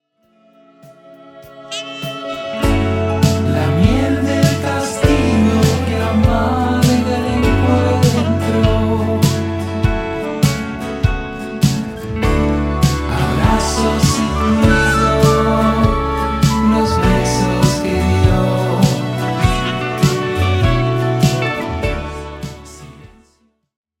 Grabado en casa.
Guitarras
Saxo y flauta traversa